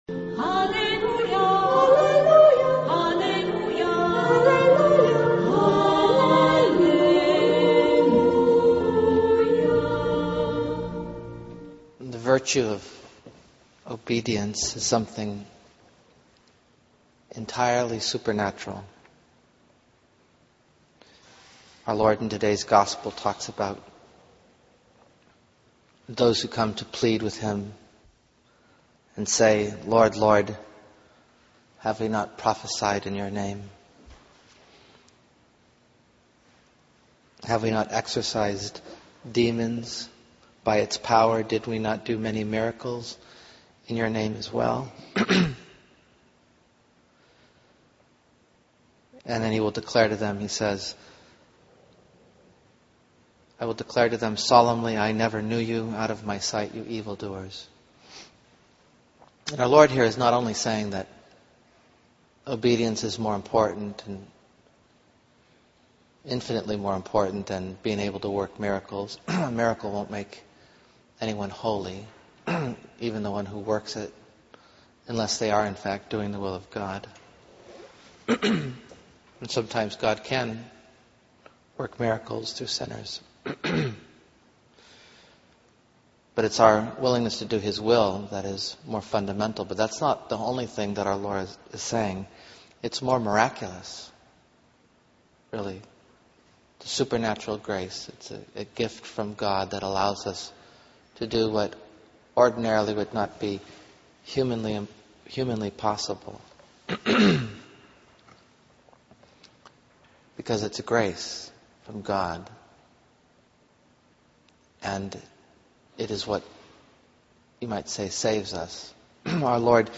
This is a homily recorded from last Friday's?Mass that we are only now posting.?